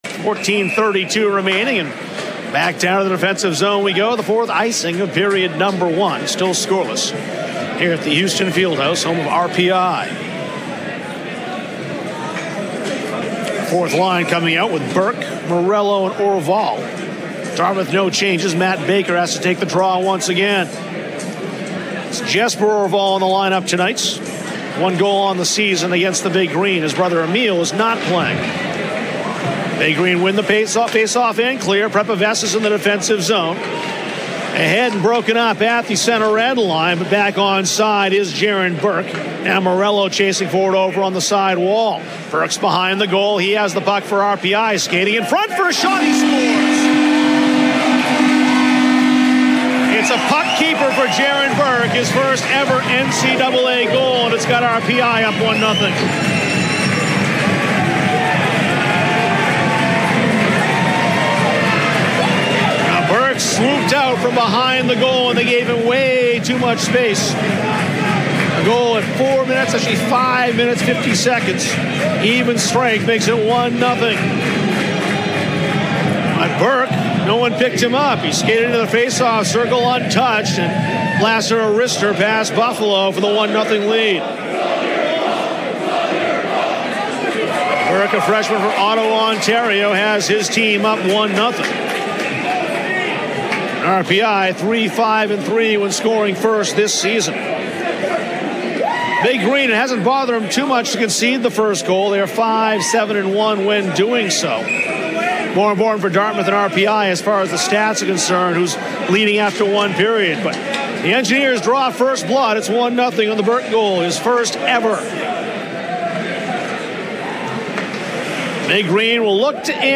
RADIO: 2019 Dartmouth College Mens Hockey Continuous Action
unedited-rpi-pxp-online-audio-converter-com.mp3